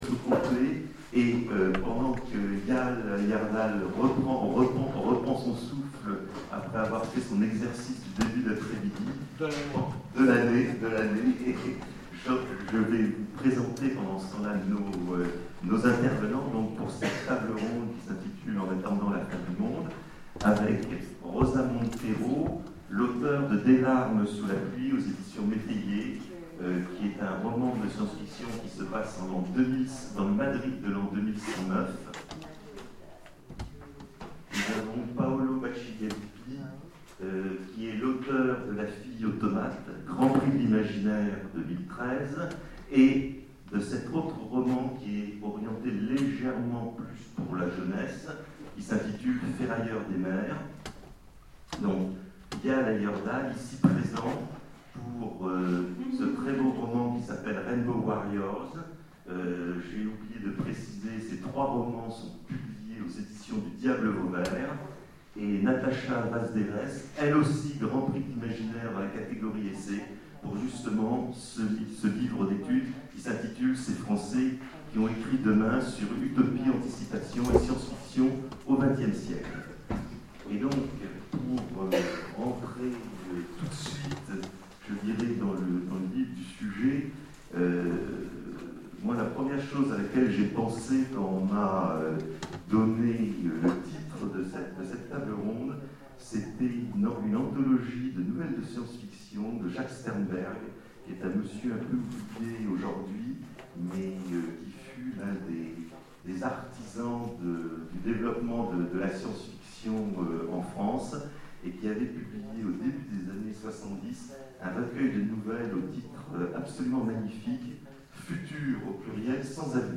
Etonnants Voyageurs 2013 : Conférence En attendant la fin du monde